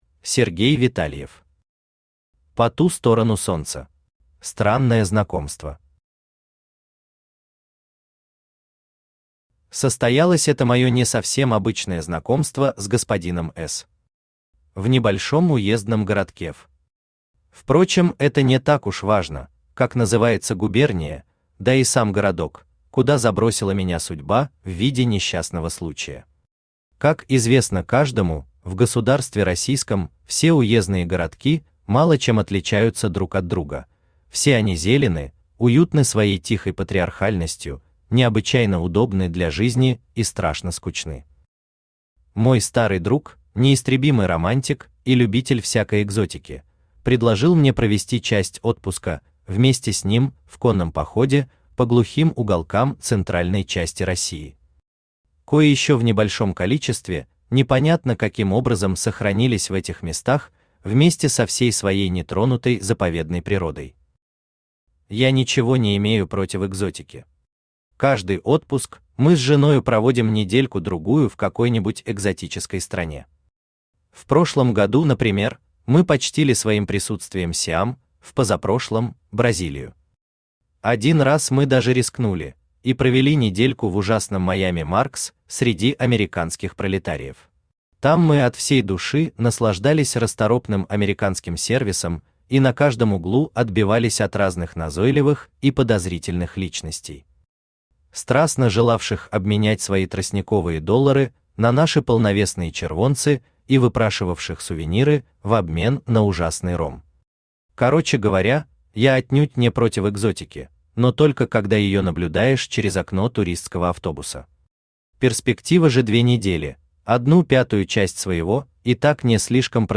Аудиокнига По ту сторону Солнца | Библиотека аудиокниг
Aудиокнига По ту сторону Солнца Автор Сергей Витальев Читает аудиокнигу Авточтец ЛитРес.